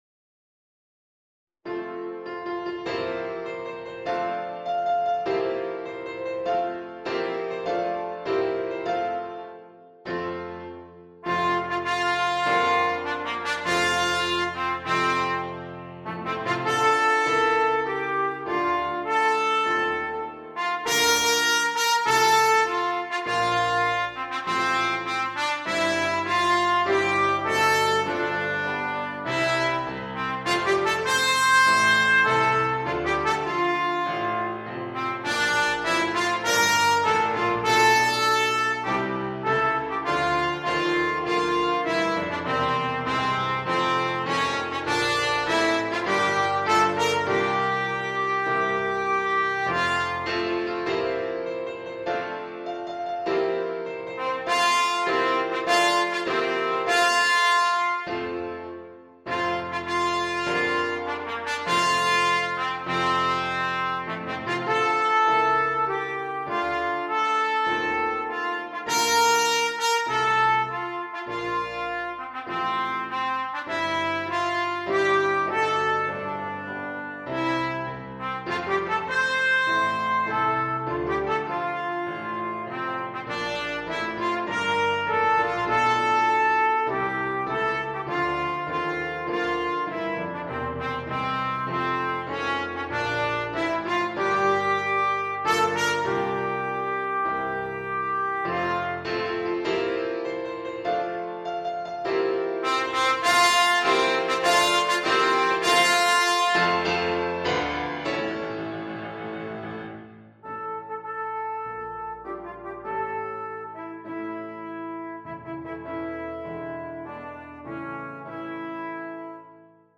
Solo Bb Trumpet and Piano in the lower key of F.
The MP3 was recorded with NotePerformer 3.